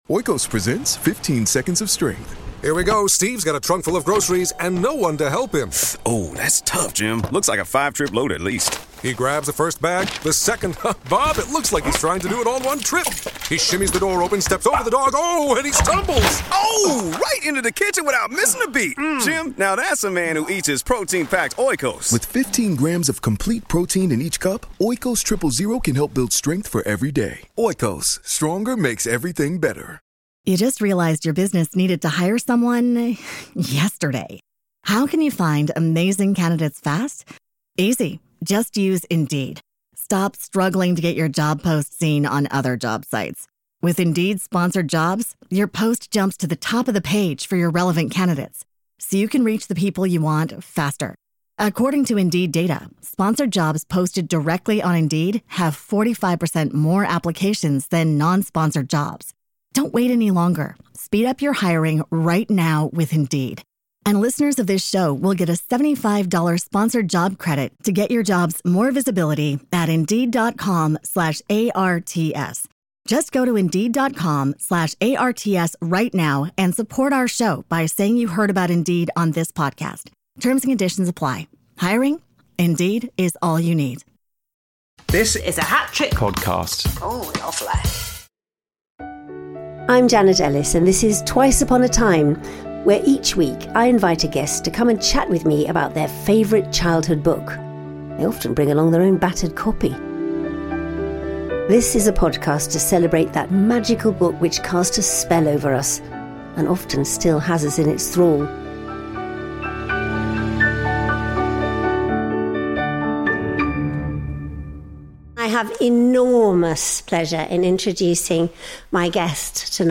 In this show, recorded LIVE at the Lyric Theatre Hammersmith, Janet is delighted to welcome actor, writer and magician Andy Nyman to discuss his choice of spooky of book (so spooky he never could bring himself to read it, but was fascinated by the pictures within...).